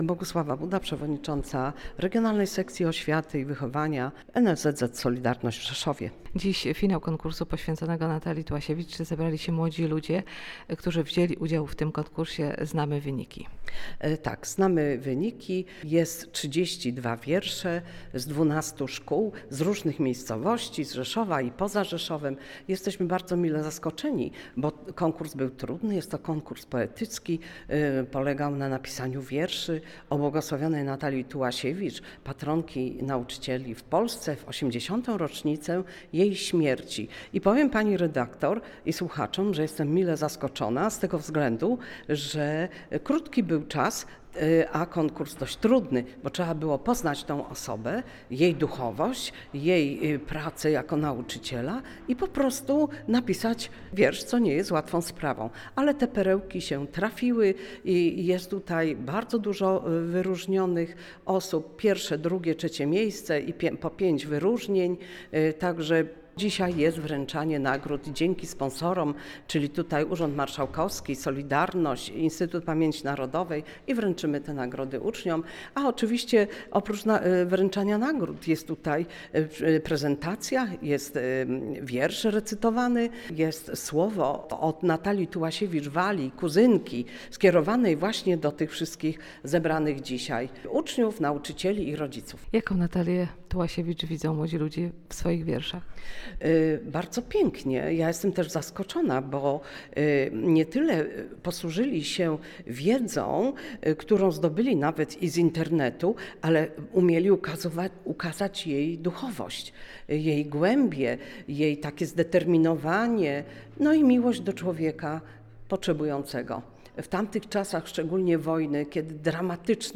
W dniu 8 kwietnia 2025 r. w I liceum Ogólnokształcącym w Rzeszowie miało miejsce uroczyste wręczenie nagród laureatom i finalistom Wojewódzkiego konkursu Poetyckiego skierowanego do uczniów szkół podstawowych i ponadpodstawowych województwa podkarpackiego.
Reportaż w Radiu VIA >>>